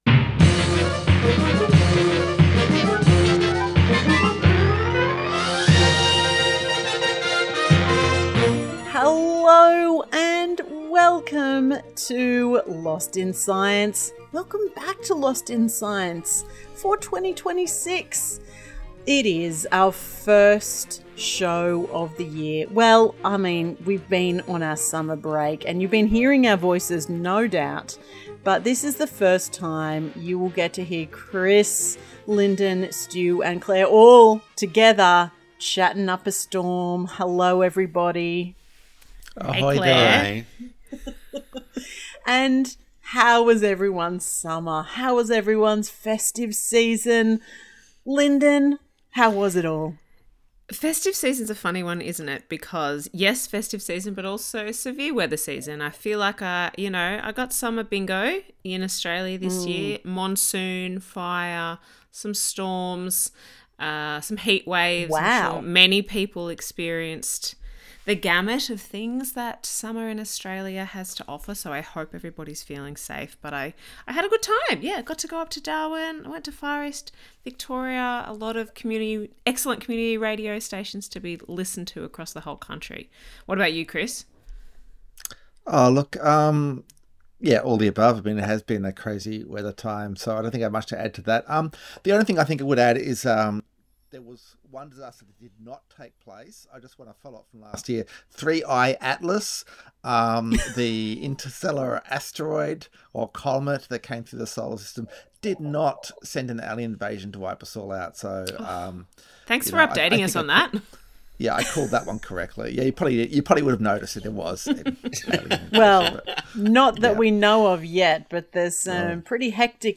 Tweet Lost in Science Thursday 8:30am to 9:00am Entertaining news and discussion about research that has impact on society and providing a wide range of science and technology news.